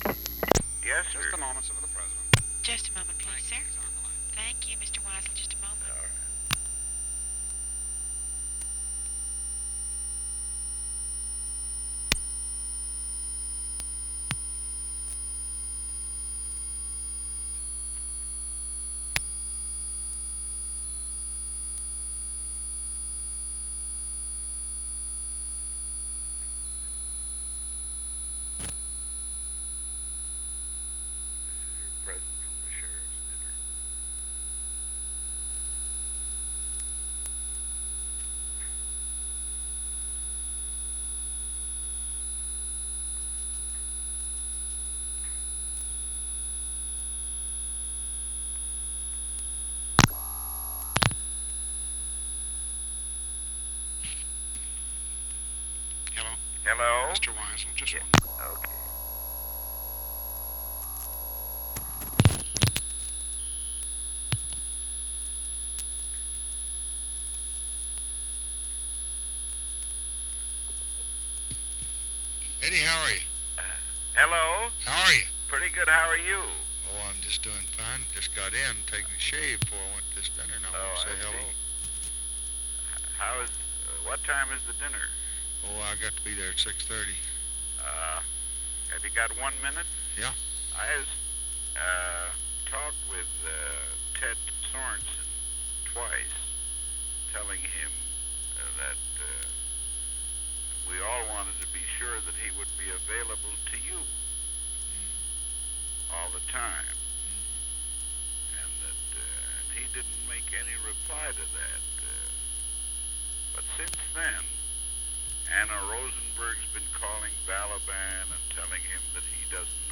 Conversation
Secret White House Tapes